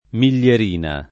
[ mil’l’er & na ]